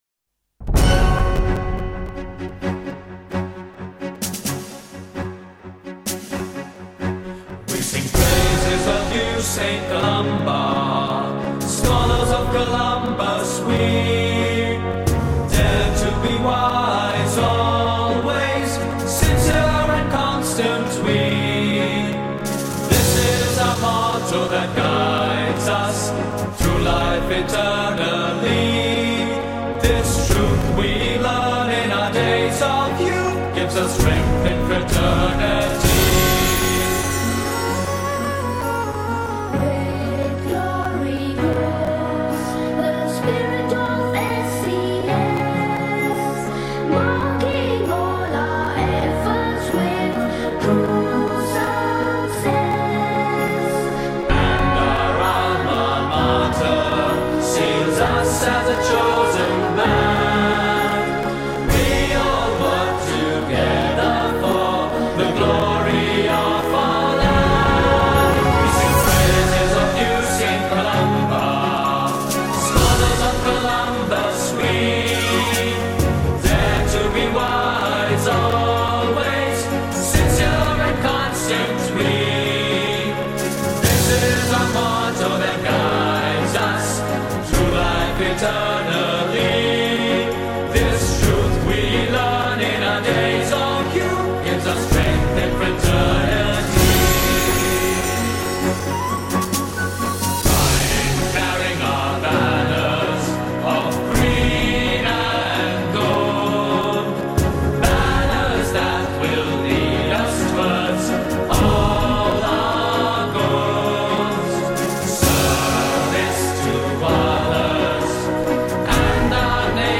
School Anthem